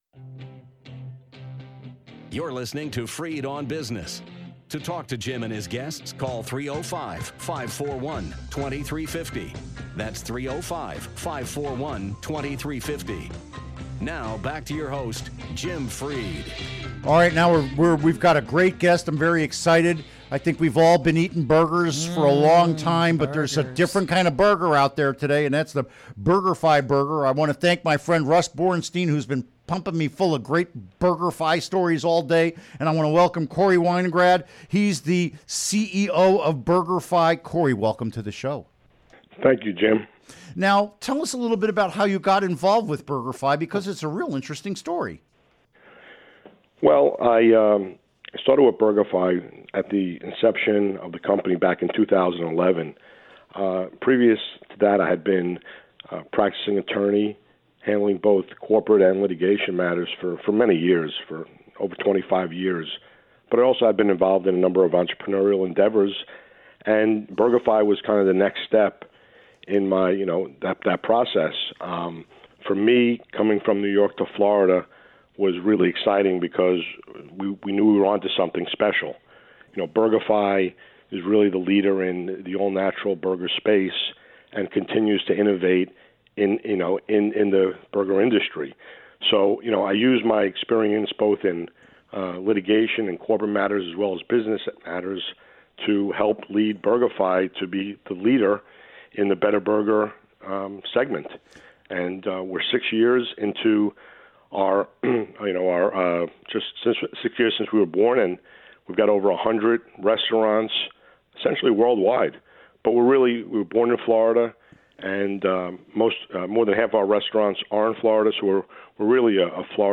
Interview Segment Download Now!